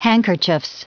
Prononciation du mot handkerchieves en anglais (fichier audio)
Prononciation du mot : handkerchieves